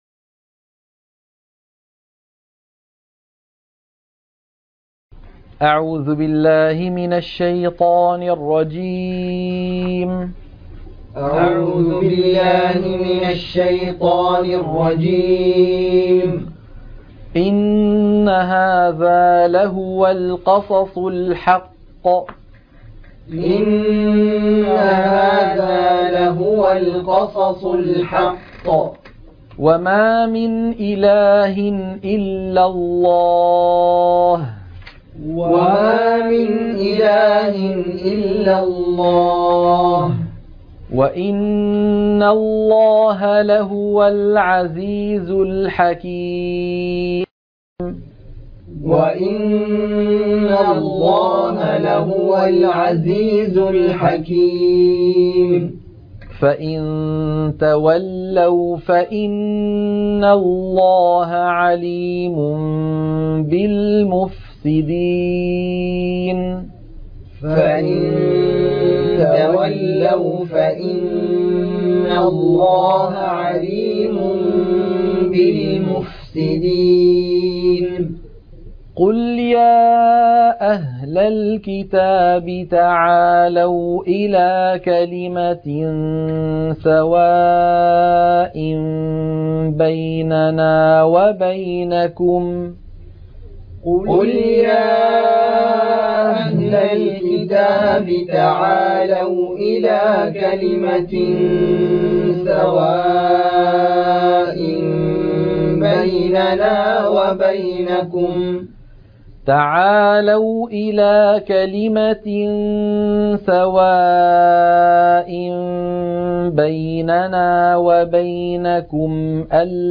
القرآن الكريم وعلومه     التجويد و أحكام التلاوة وشروح المتون